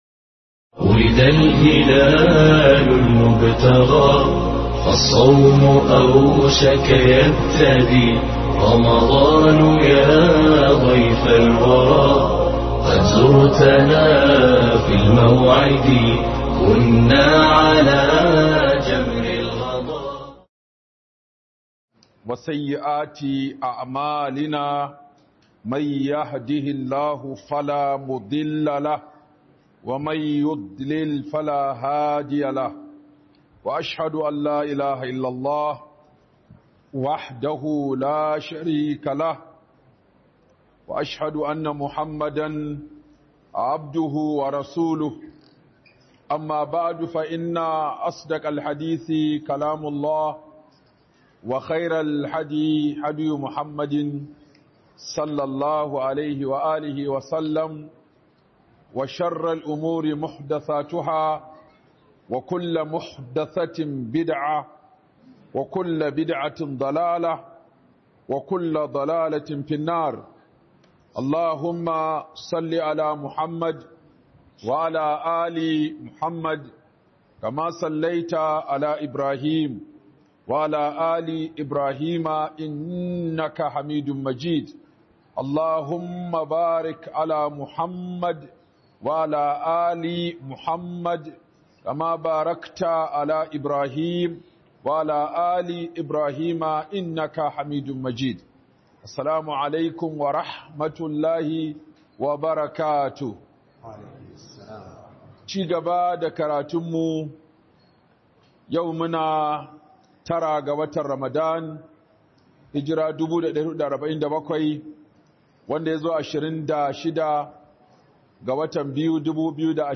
Scholar Sheikh Aminu Ibrahim Daurawa